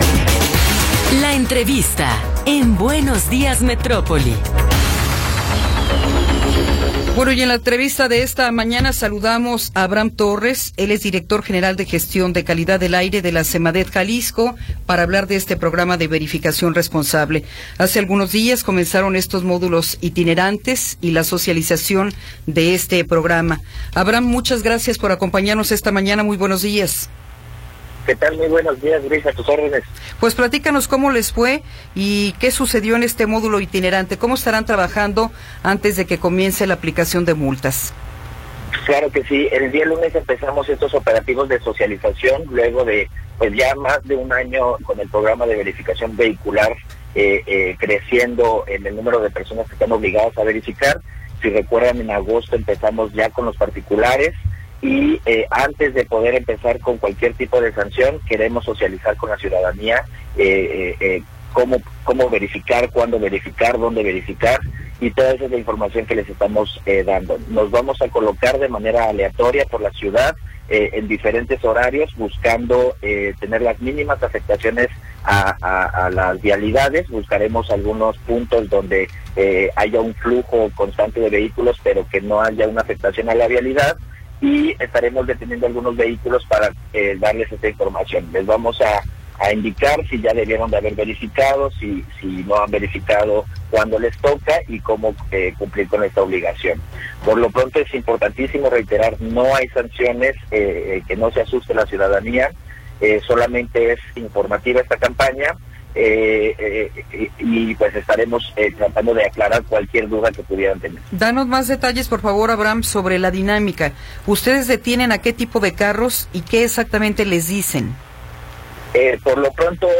Entrevista con Abraham Torres Andrade